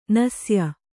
♪ nasya